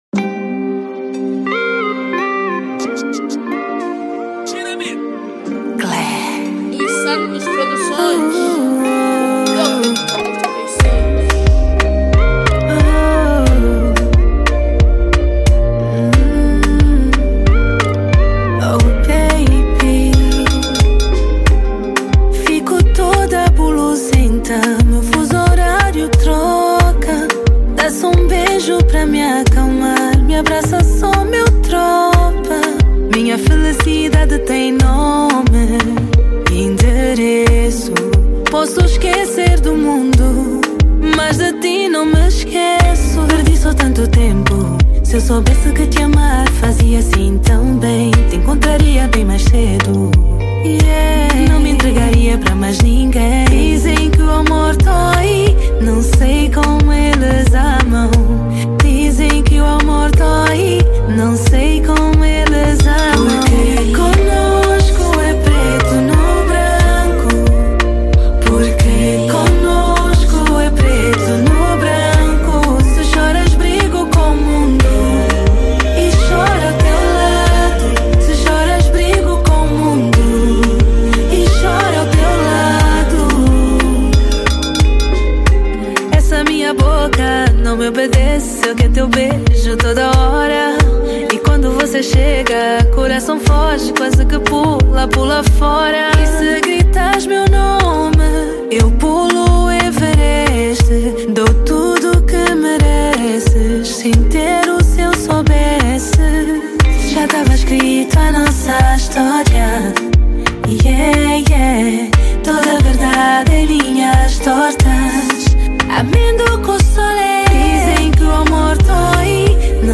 Categoria   Kizomba